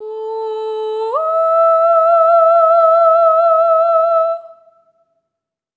SOP5TH A4 -L.wav